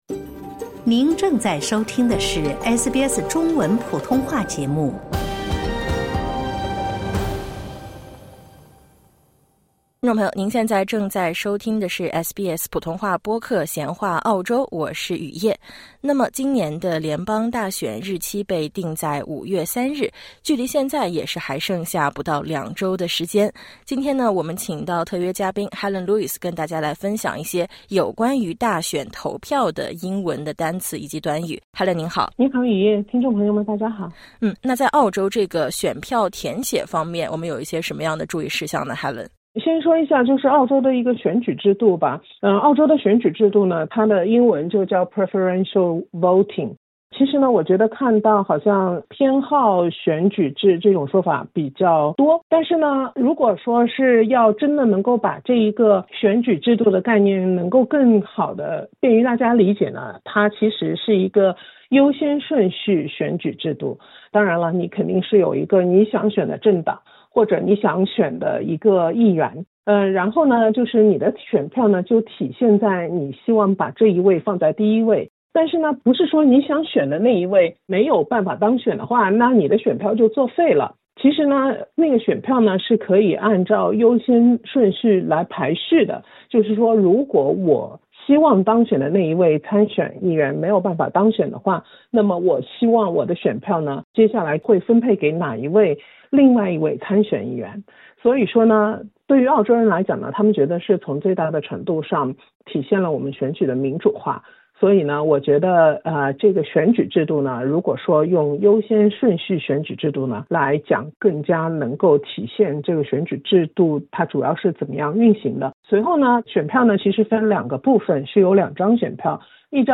SBS中文在墨尔本街头采访到几位Z世代选民，